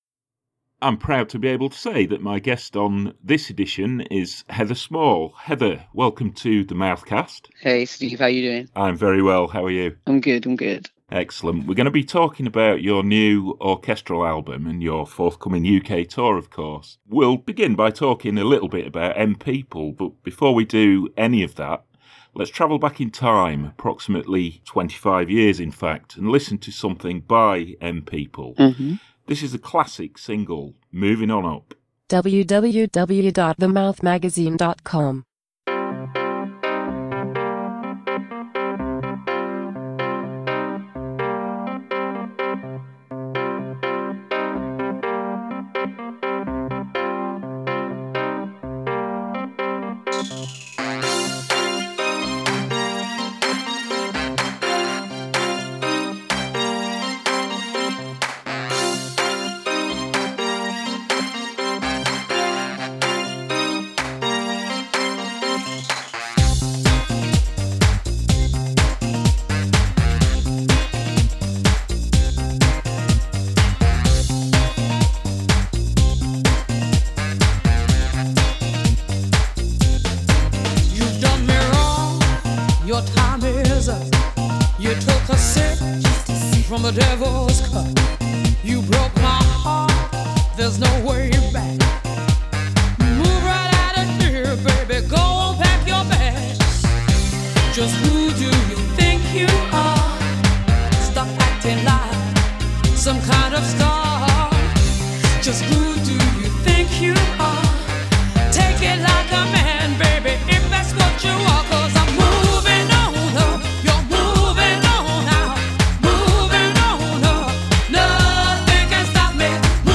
Now, on the verge of a new orchestral album and extensive UK tour, Heather talks to The Mouth Magazine…